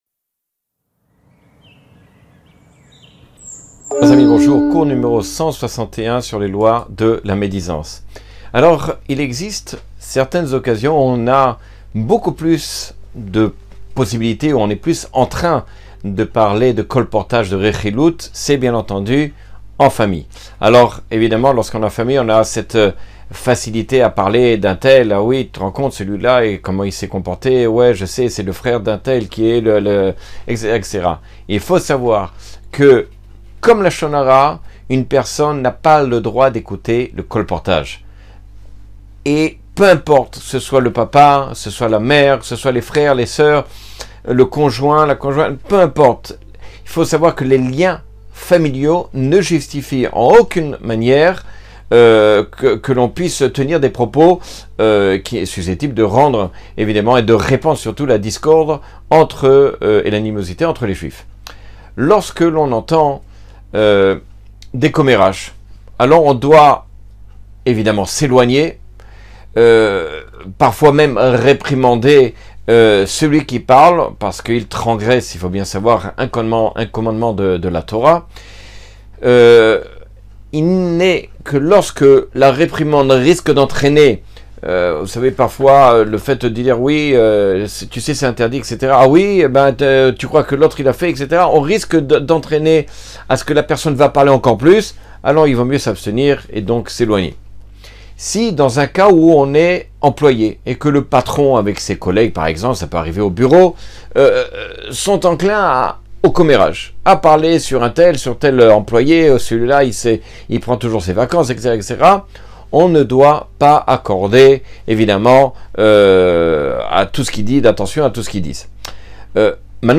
Cours 161 sur les lois du lashon hara.